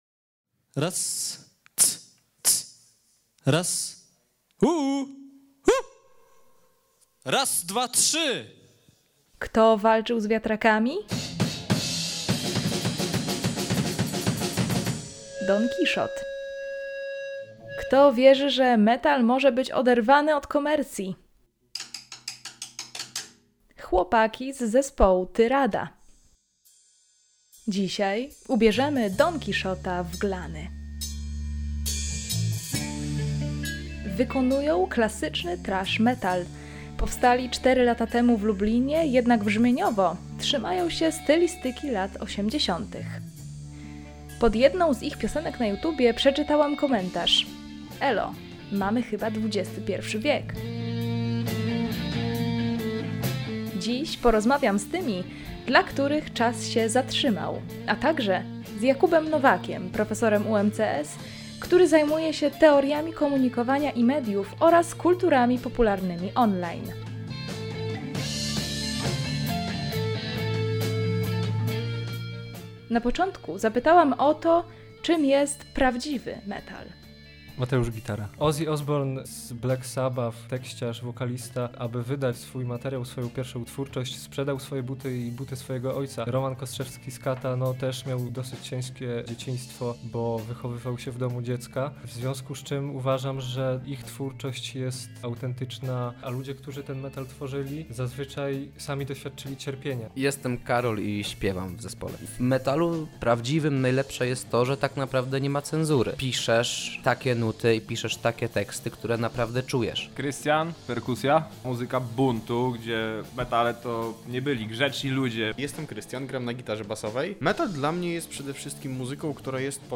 Gra on klasyczny thrash metal rodem z lat 80’. Wierność tej stylistyce nie do końca przekłada się na zyski.